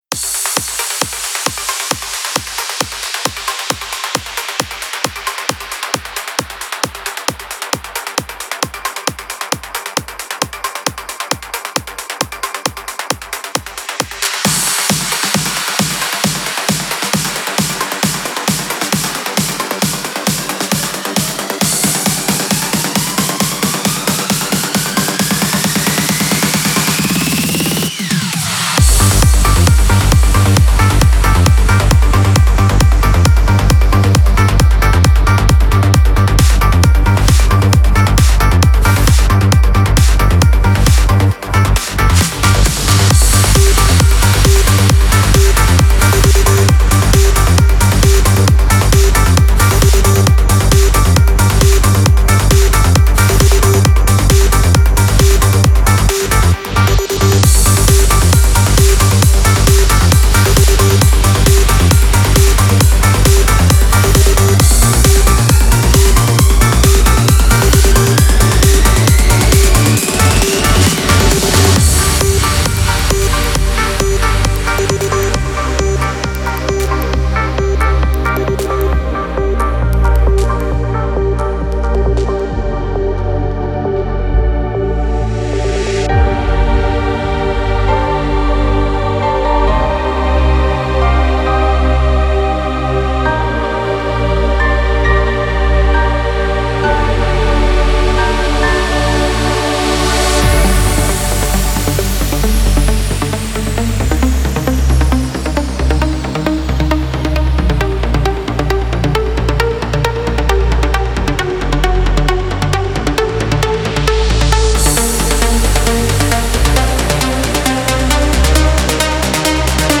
Стиль: Trance / Progressive Trance